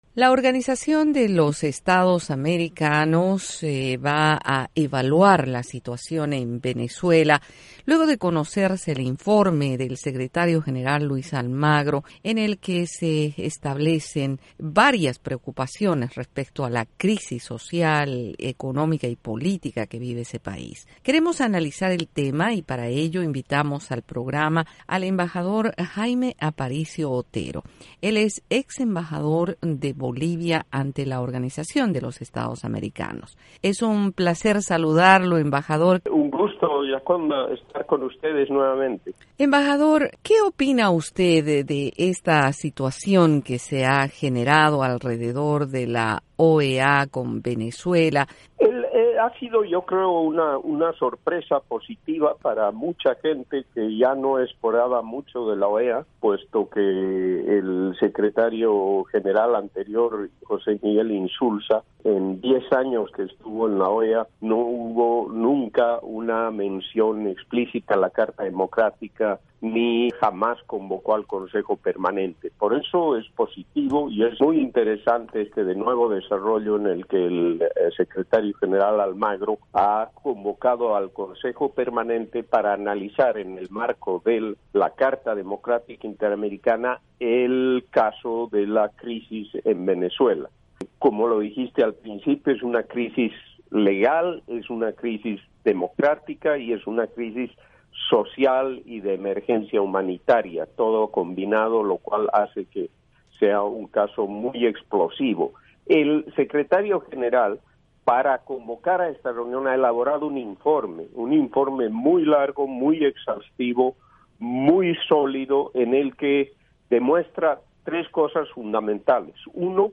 El embajador Jaime Aparicio, exrepresentante de Bolivia ante la OEA, dijo en una entrevista con la Voz de América, que las posiciones de las naciones luego del cambio de Secretario General anticipan un empate técnico.